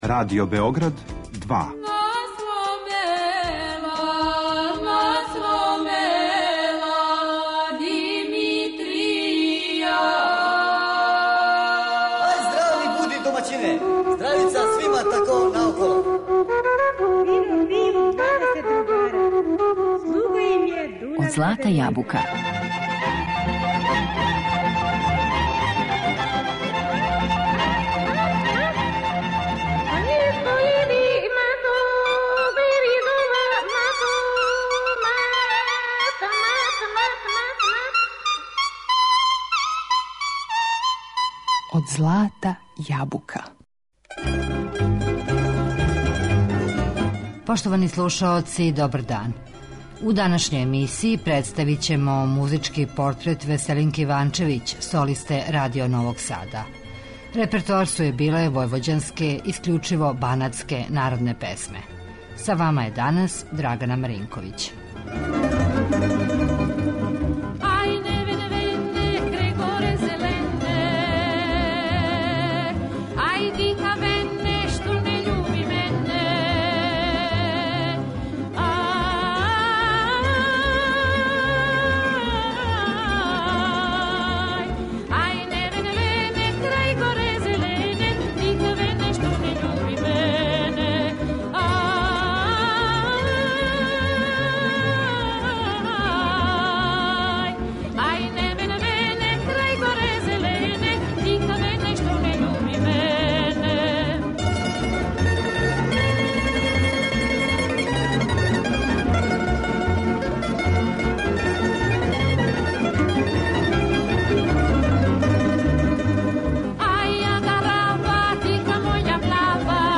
војвођанске народне песме